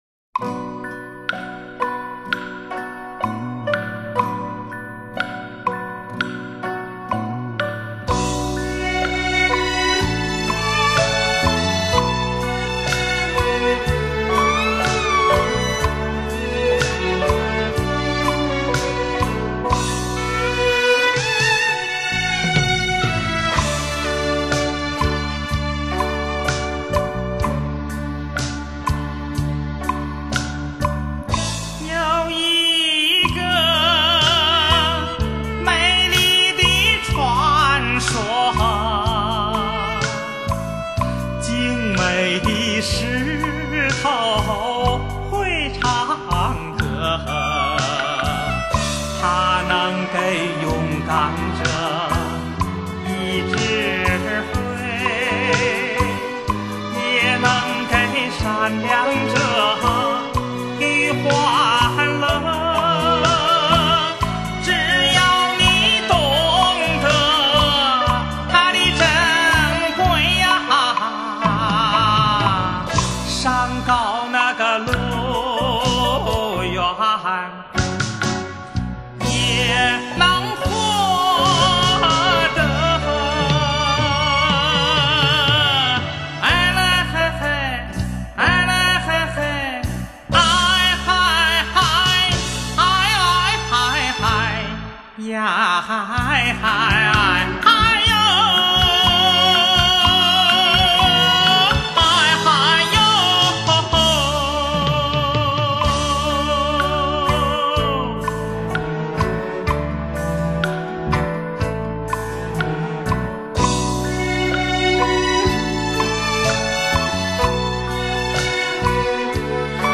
他的演唱声音明亮，行腔甜润，吐字清晰，韵味醇厚。